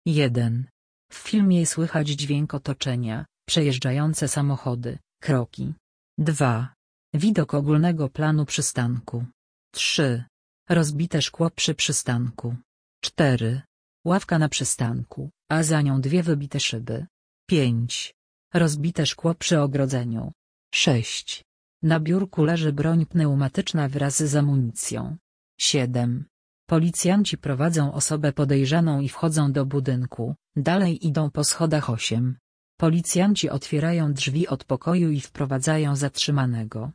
Audiodeskrypcja filmu - plik mp3